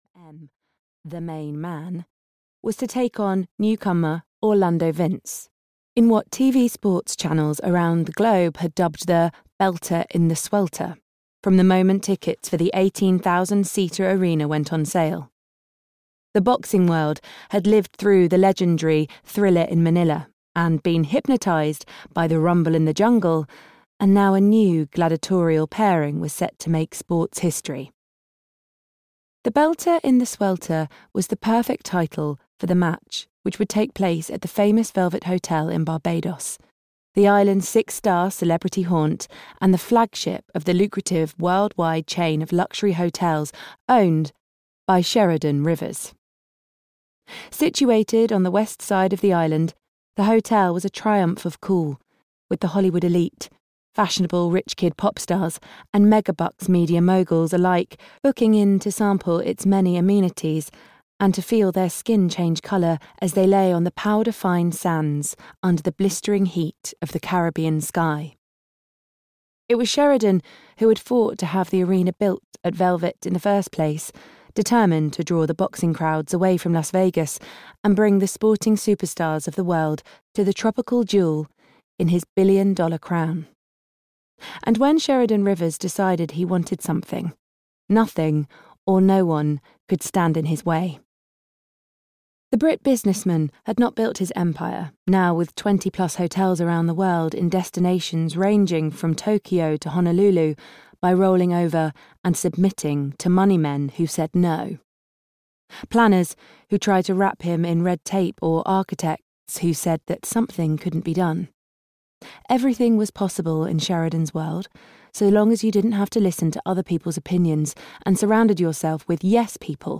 Lovers and Liars (EN) audiokniha
Ukázka z knihy